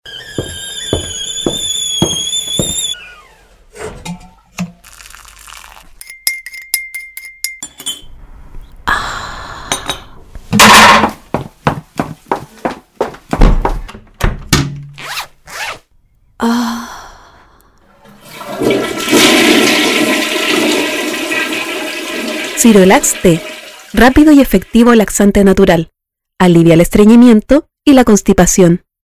Propuesta Radial para camapaña " Ciruelax"
ciruelaxteradio30segundos.mp3